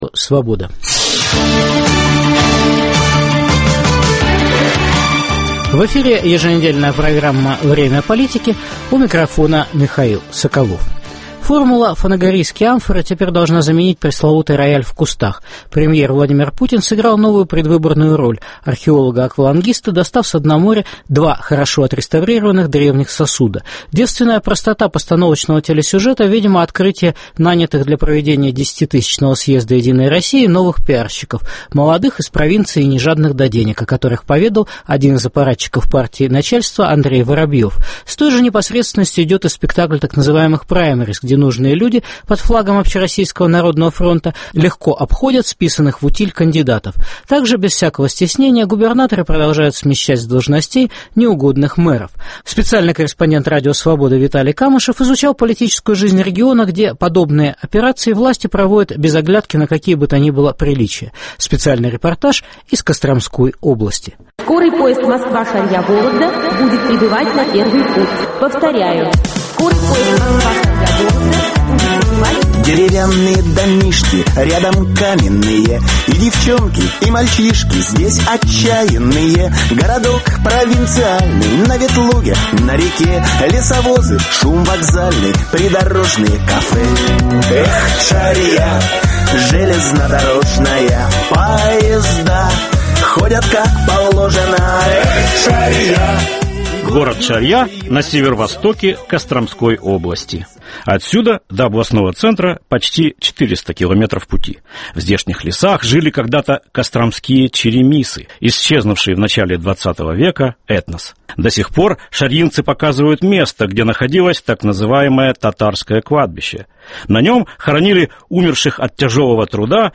Шарья пишет президенту Медведеву. Губернатор Слюняев на костромском воеводстве. Специальный репортаж